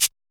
LINN SHAKER.wav